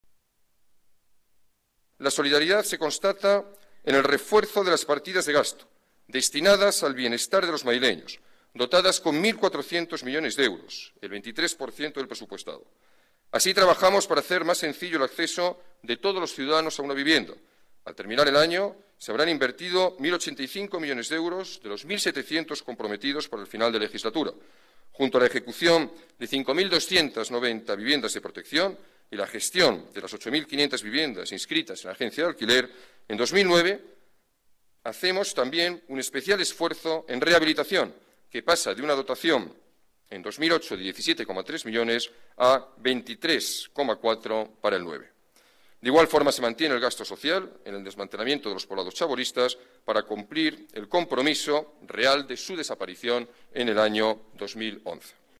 Gallardón confirma su candidatura a la Alcaldía en 2011 en el Pleno sobre el Debate del Estado de la Ciudad
Nueva ventana:Alberto Ruiz-Gallardón, alcalde, anuncia su candidatura en 2011
GallardonDebateEstadoCiudadGastoEnViviendas-19-06.mp3